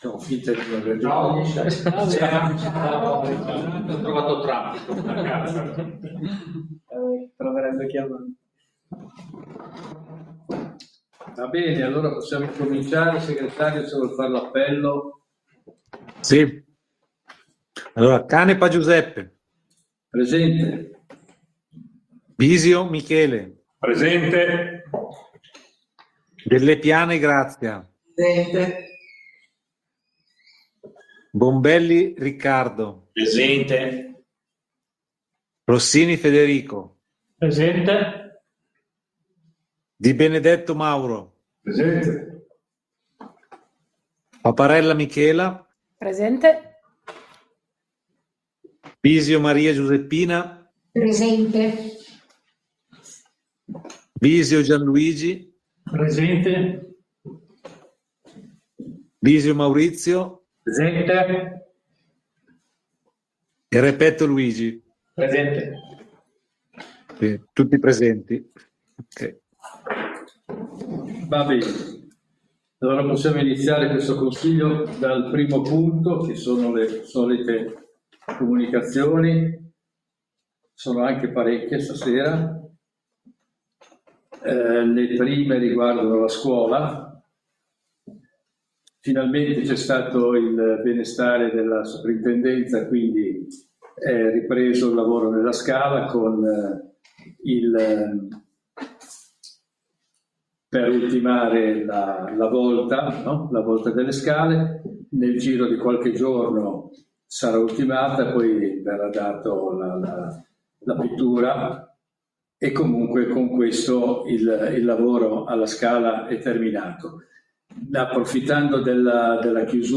Seduta del Consiglio Comunale del 18/04/2025